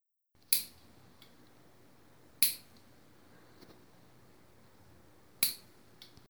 Chasquido
Grabación sonora que capta el sonido de un chasquido. Éste chasquido puede deberse a unas pinzas cerrándose rápidamente o incluso al accionador de un mechero automático (no identificado concretamente).